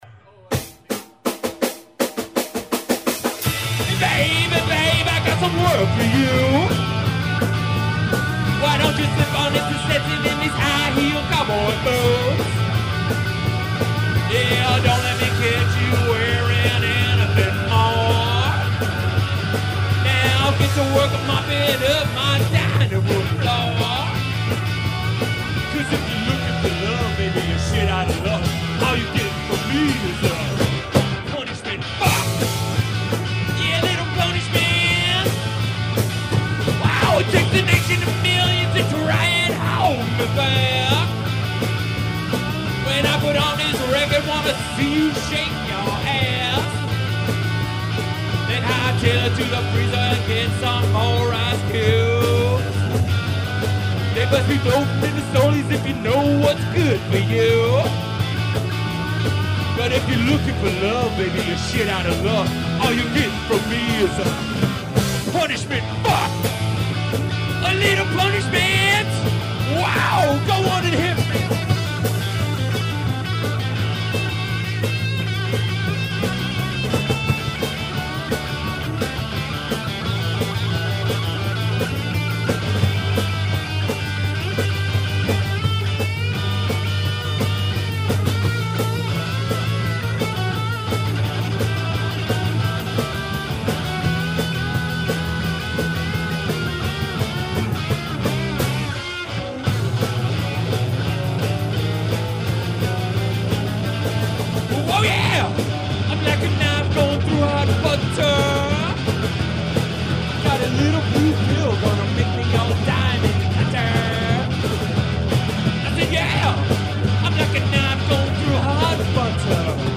Living Room Taipei 06/10/2005